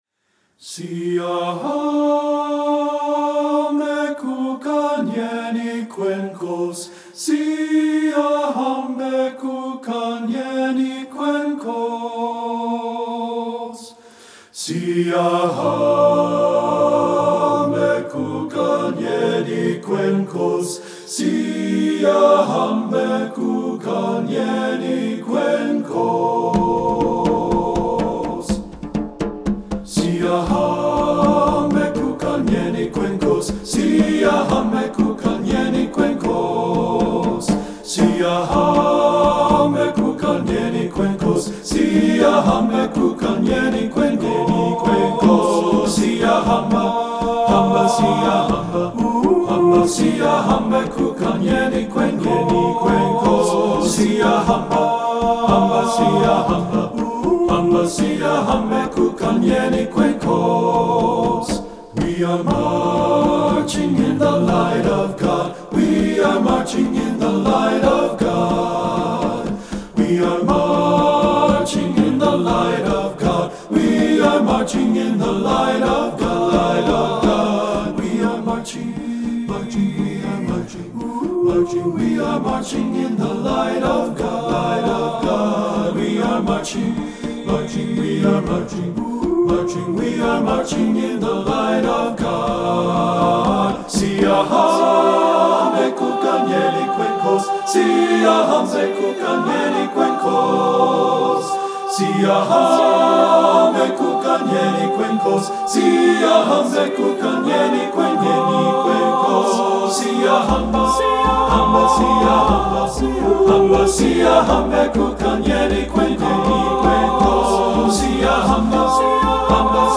Voicing: TBB a cappella